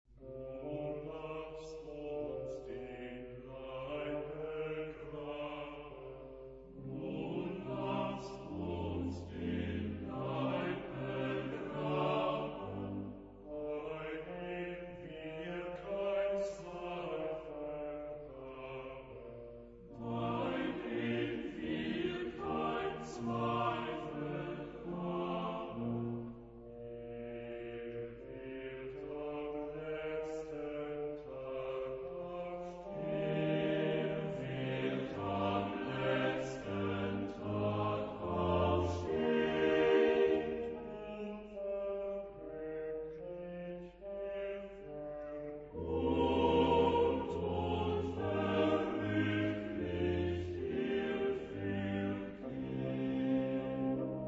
Genre-Stil-Form: weltlich ; romantisch
Chorgattung: SATB  (4 gemischter Chor Stimmen )
Instrumentation: Blasorchester  (12 Instrumentalstimme(n))
Instrumente: Oboe (2) ; Klarinette in B (2) ; Horn (2) ; Trompete (2) ; Posaune (3) ; Orgel (1)
von Kammerchor Stuttgart gesungen unter der Leitung von Frieder Bernius